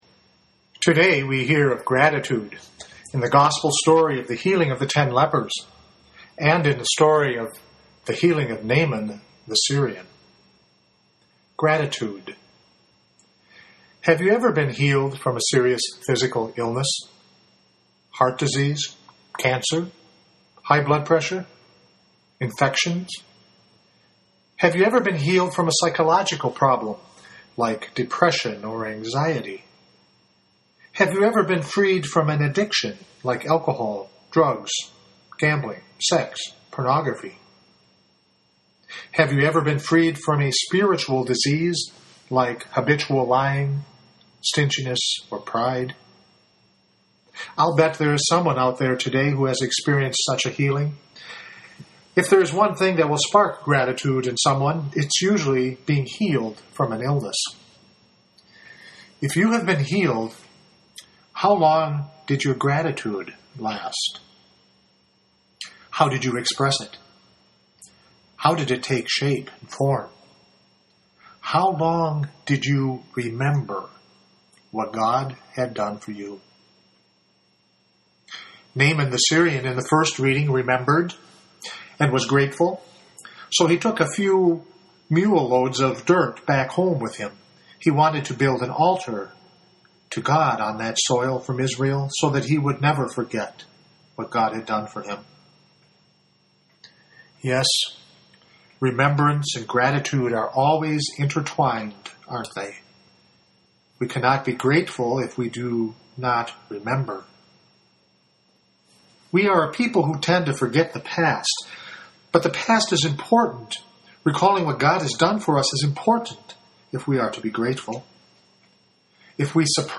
This entry was posted in homilies.